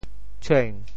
喘 部首拼音 部首 口 总笔划 12 部外笔划 9 普通话 chuǎn 潮州发音 潮州 cuêng2 文 中文解释 喘 <动> (形声。